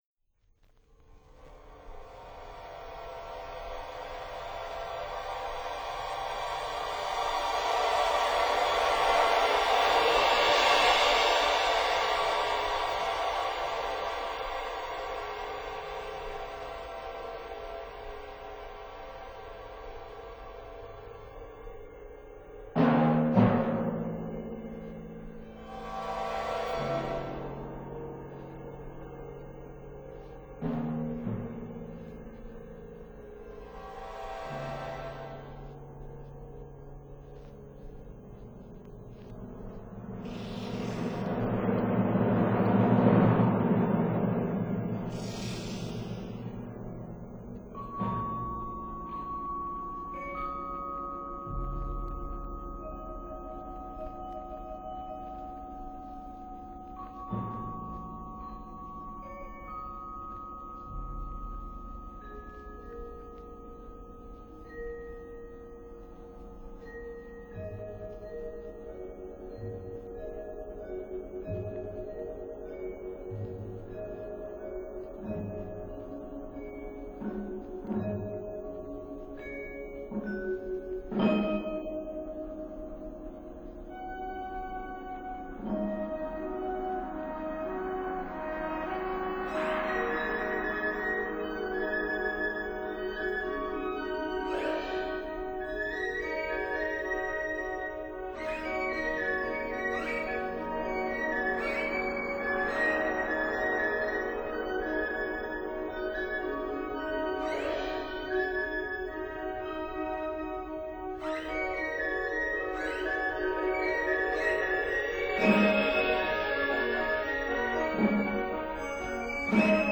for solo oboe, two trumpets, timpani and strings